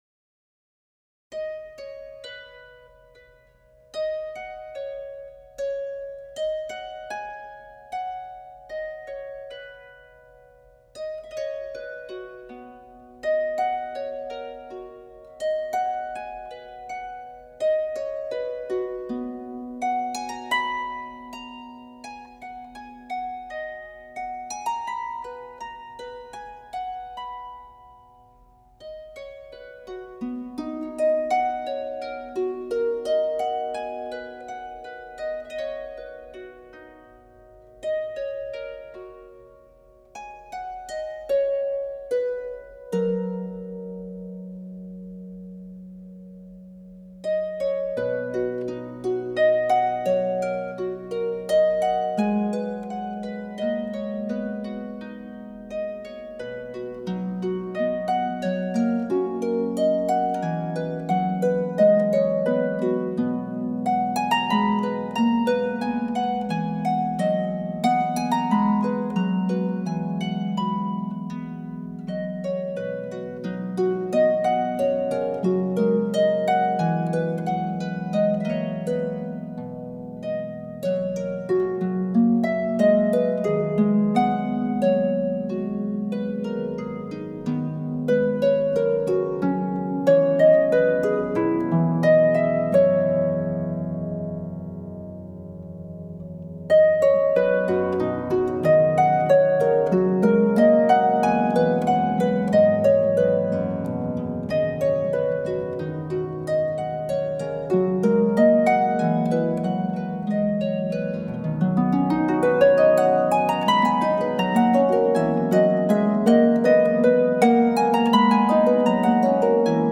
traditional hymn